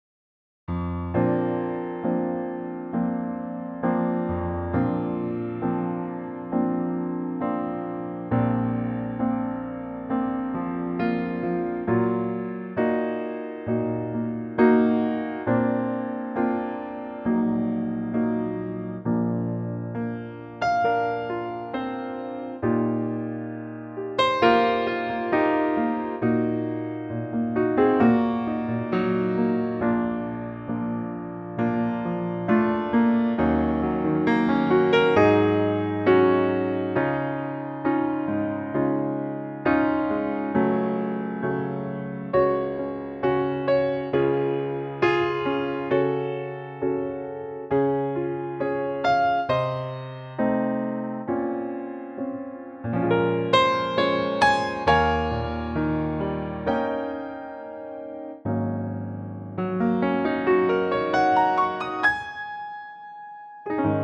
Unique Backing Tracks
key - Bb (and Db) - vocal range - D to Bb
Gorgeous piano only arrangement